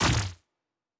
pgs/Assets/Audio/Sci-Fi Sounds/Weapons/Weapon 06 Shoot 3.wav at master
Weapon 06 Shoot 3.wav